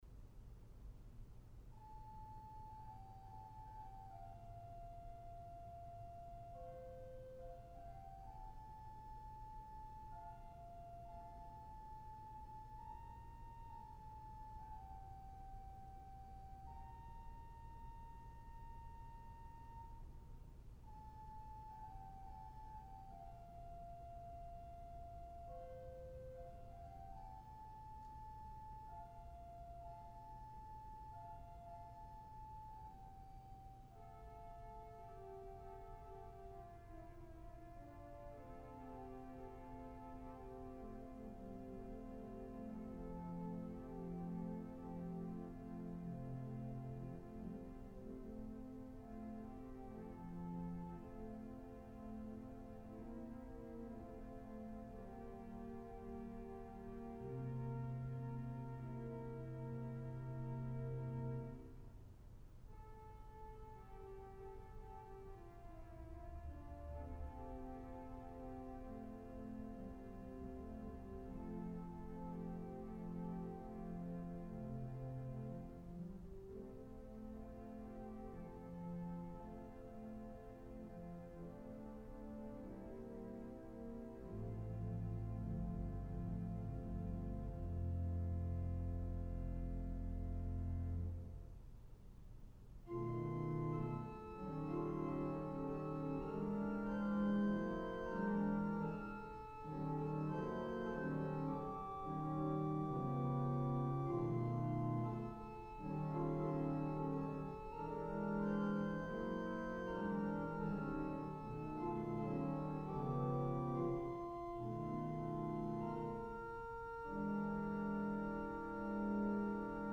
• Music Type: Organ